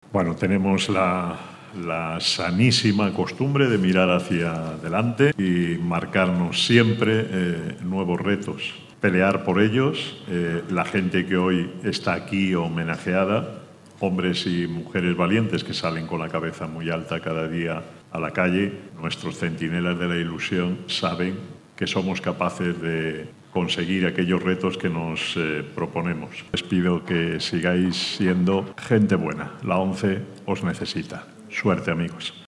Lo hacía desde la sede del Hotel Ilunion Pío XII en Madrid, cuyo salón de actos se convirtió durante algunas horas en una calle… Sí, en la ‘Calle de la Buena Gente ONCE’, en donde transcurrió la cena de gala y entrega de galardones que reconocen anualmente, y ya van 15 ediciones, el esfuerzo, la actitud en el desempeño de su trabajo, la implicación con los clientes  y el compromiso con la labor social de la institución de cada uno de ellos.